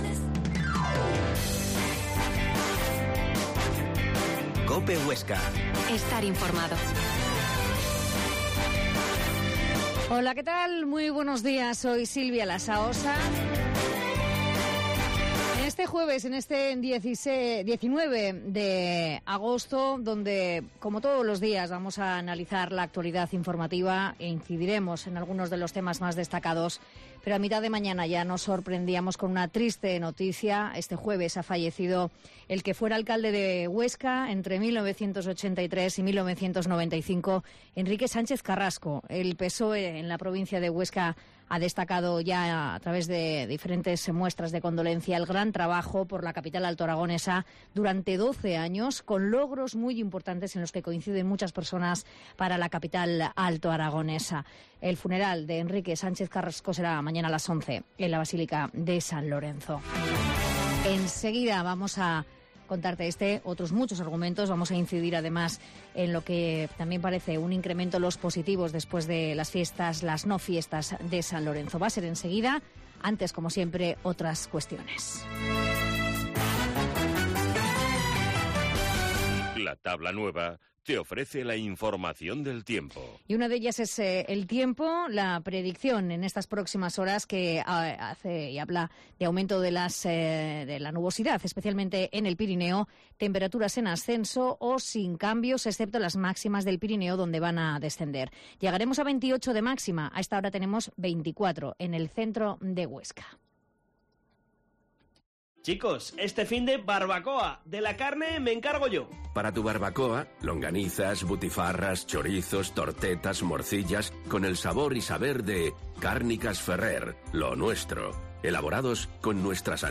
La Mañana en COPE Huesca - Magazine